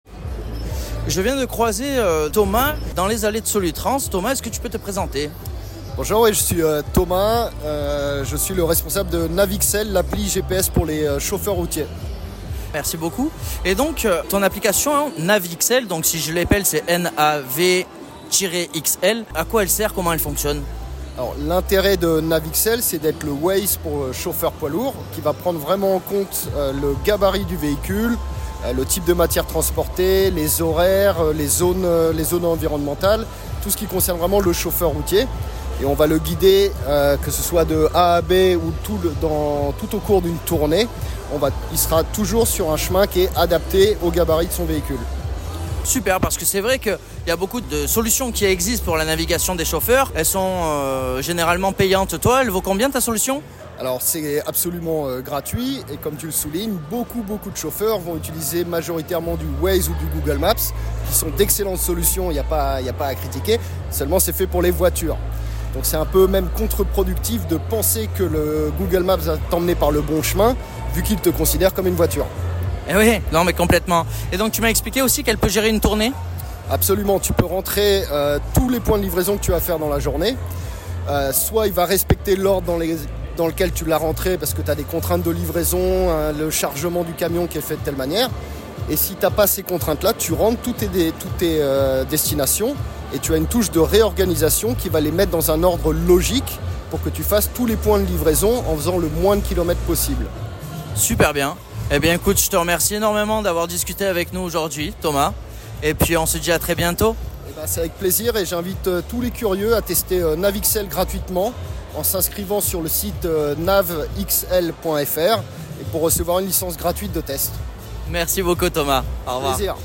Solutrans 2023
Le 22/11/2023 – EUREXPO Chassieu – SOLUTRANS
INTERVIEW